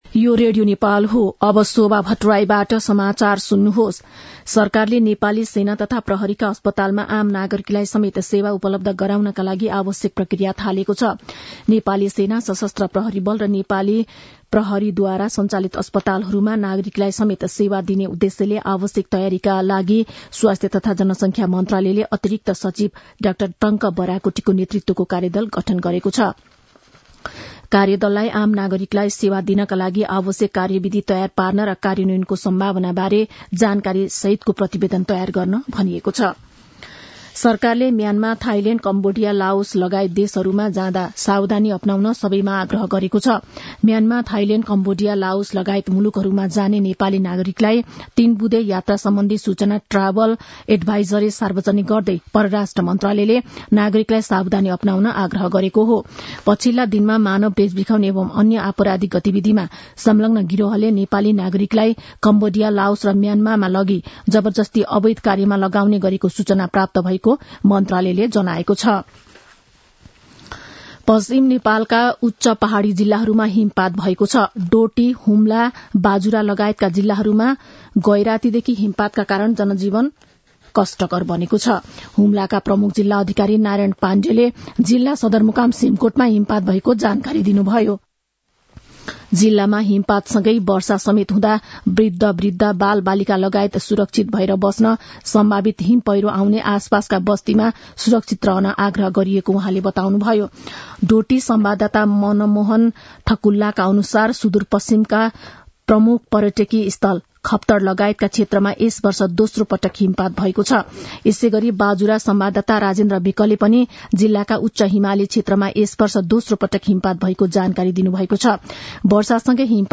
मध्यान्ह १२ बजेको नेपाली समाचार : १० पुष , २०८१
12-am-nepali-news-1-18.mp3